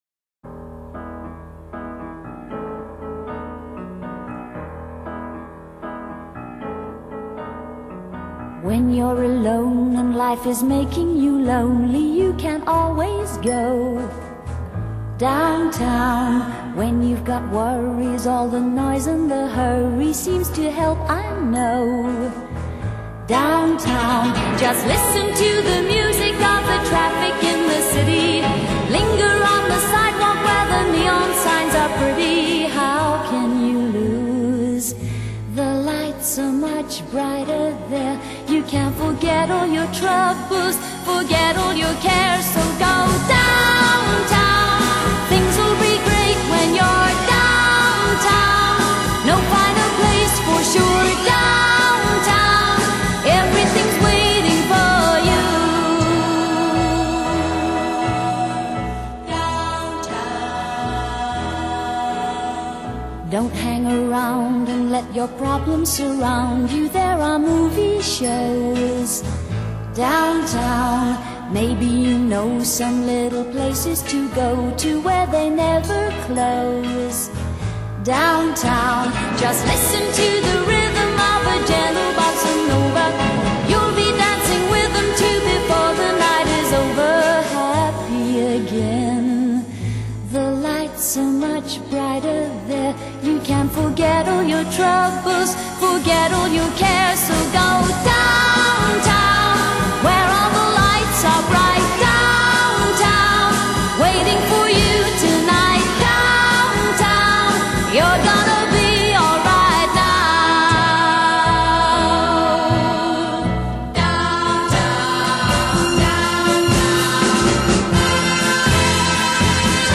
拉克拉克是第一位打入美國暢銷歌曲排行榜的英國女歌星﹐她特別拿手的是唱出中下
歌曲採漸行漸高的模式﹐共有重複的三段﹐每段內
又包括三部分﹕首句重複兩次﹐中句重複兩次﹐過門後重複末句三次﹐所以是三三結